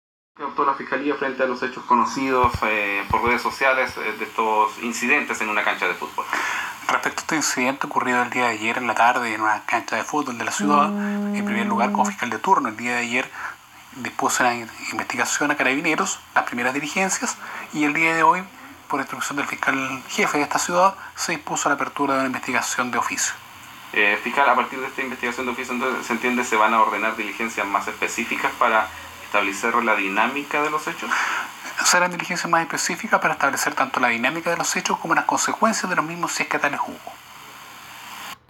Fiscal Ariel Guzmán indicó que se enviarán las órdenes de investigar respectivas, además de mencionar que hasta el momento no se han presentados personas lesionadas al Hospital Regional.